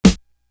The Drama Snare.wav